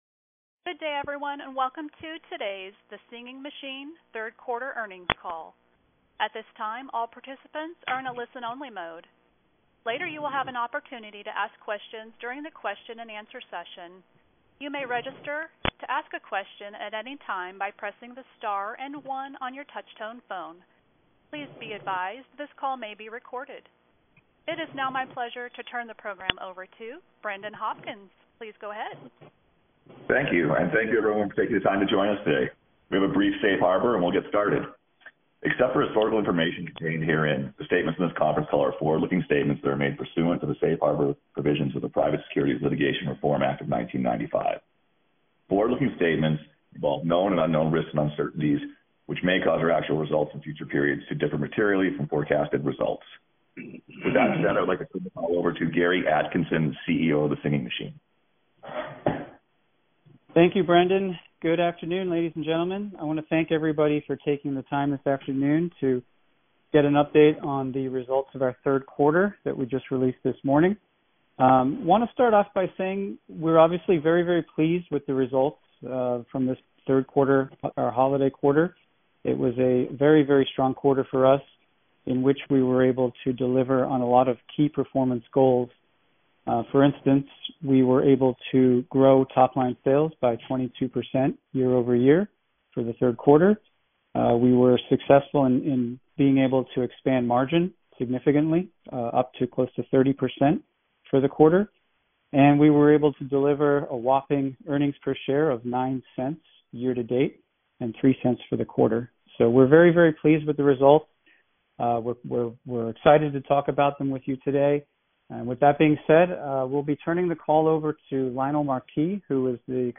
Singing Machine Co Inc — SMDM Q3 2021 Earnings Conference Call